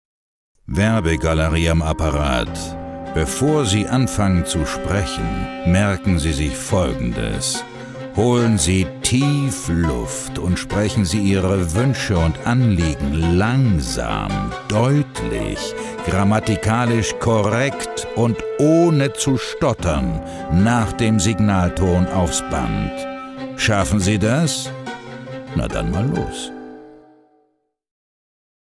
Ob kräftig und markant, autoritär oder auch in sanften, leisen Tonlagen, Joachim Kerzel beherrscht das Spiel mit seiner Stimmfarbe meisterhaft.
H Ö R B E I S P I E L E – in der finalen Tonmischung:
Kreative Telefonansage